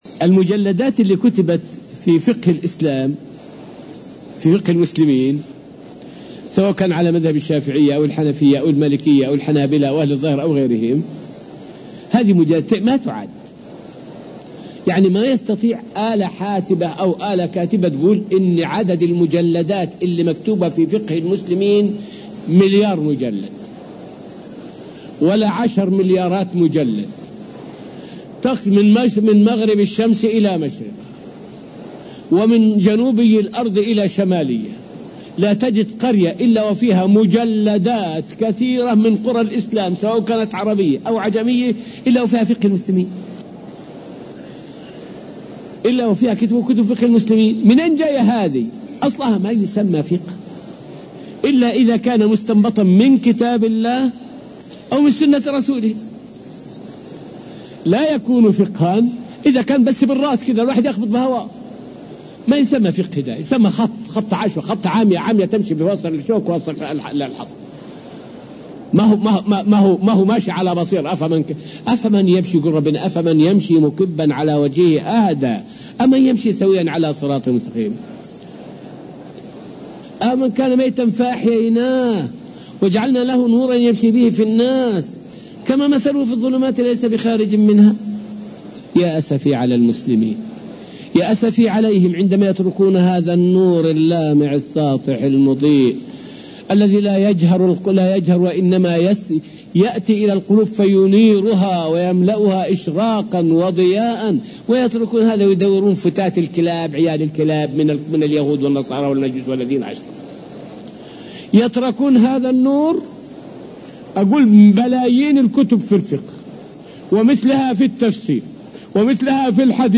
فائدة من الدرس السادس عشر من دروس تفسير سورة البقرة والتي ألقيت في المسجد النبوي الشريف حول التمسك بالكتاب والسنة سبيل السعادة.